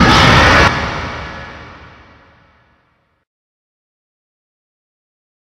Five Nights at Norman's Jumpscare Sound 5
five-nights-at-normans-jumpscare-sound-5.mp3